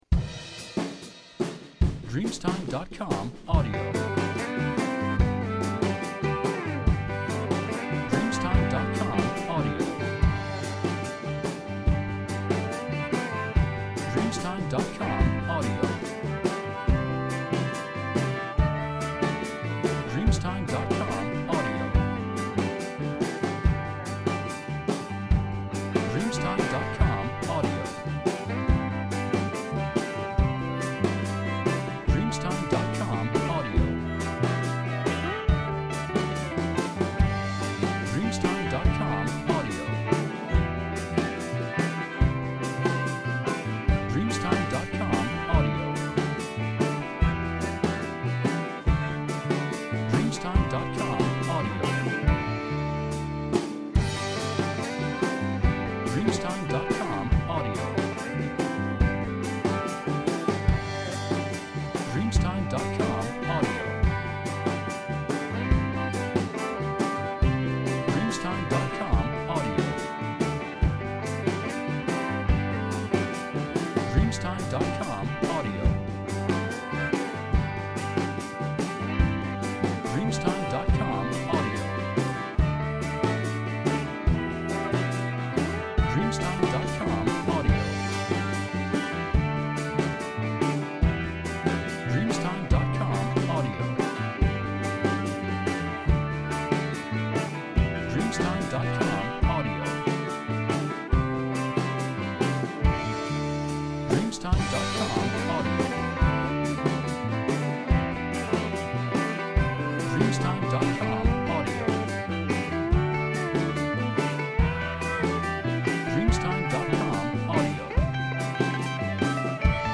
Jangle Pop Rock Music Tracks